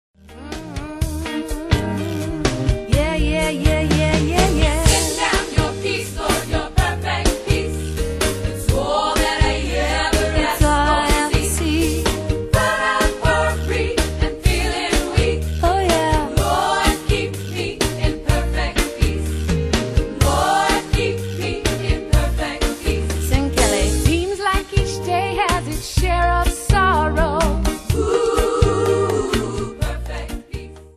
This one was written in a Jamaican style.